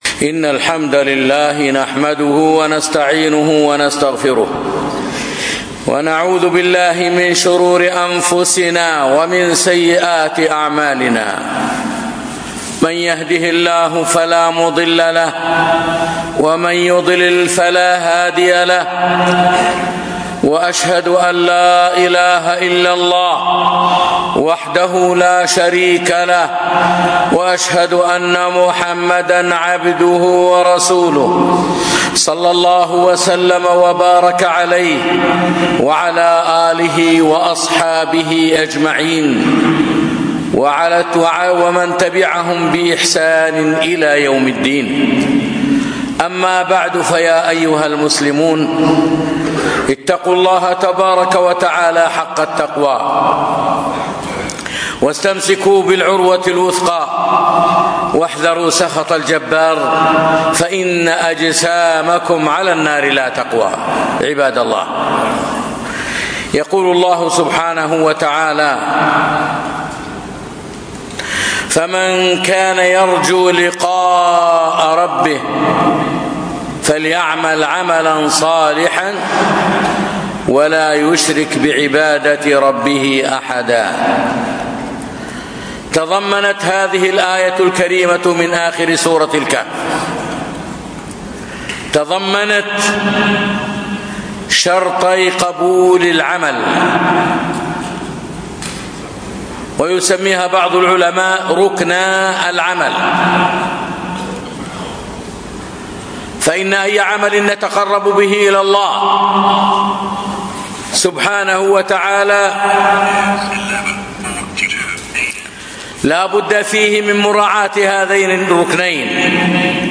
خطبة - شروط قبول العمل